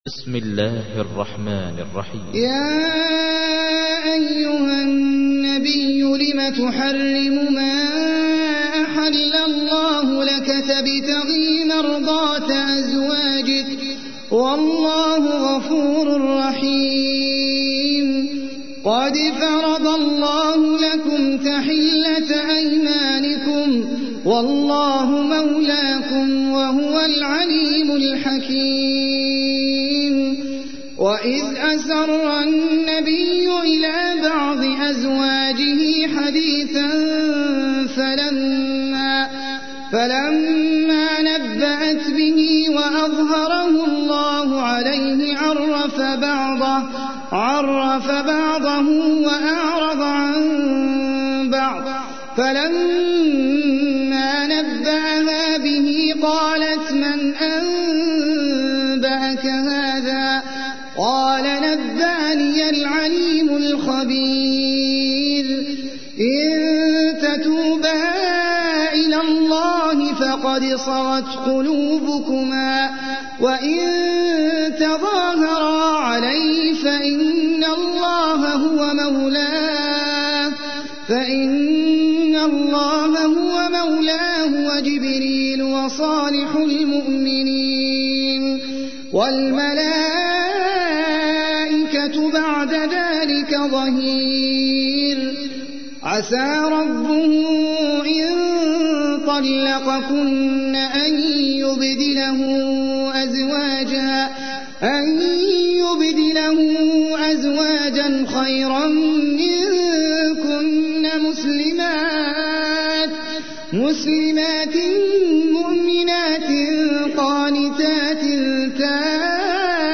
تحميل : 66. سورة التحريم / القارئ احمد العجمي / القرآن الكريم / موقع يا حسين